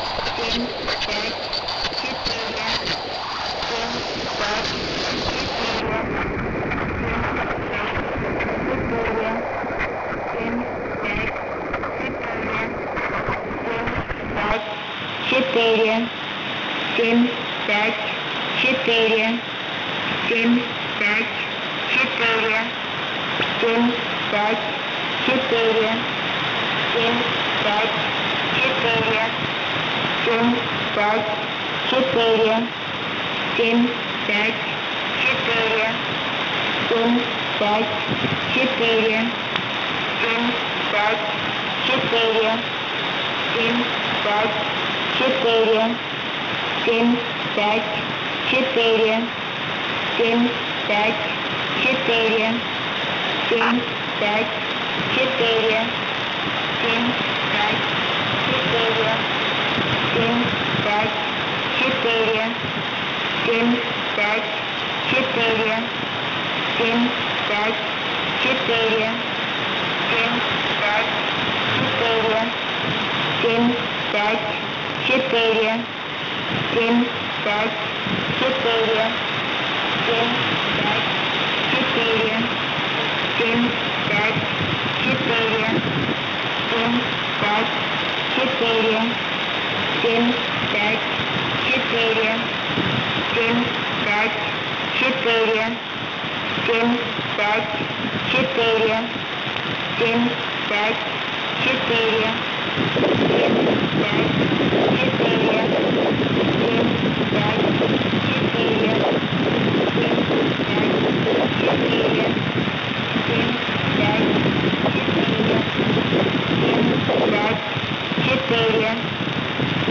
Mode: USB + Carrier